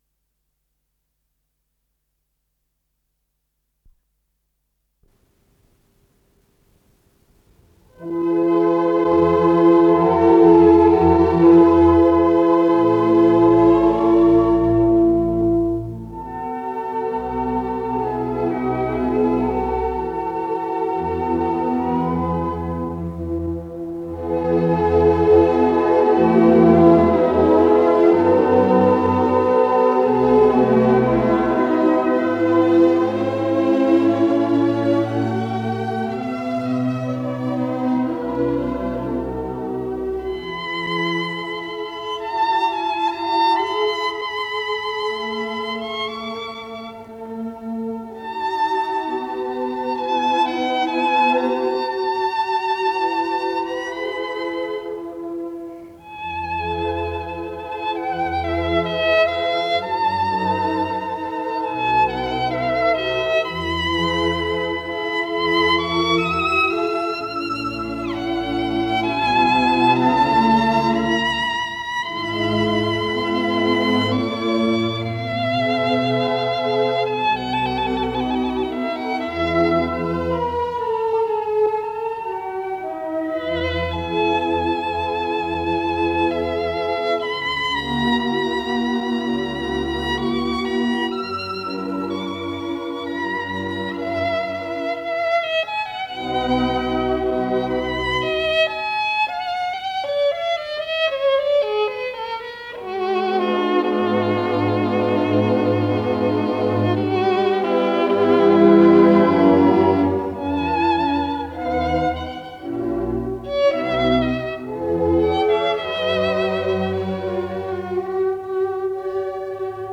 с профессиональной магнитной ленты
Название передачиАдажио для скрипки и симфонического оркестра, соч. К-261
ИсполнителиДавид Ойстрах - скрипка
АккомпаниментБерлинский филармонический оркестр
Дирижёр - Давид Ойстрах
Скорость ленты38 см/с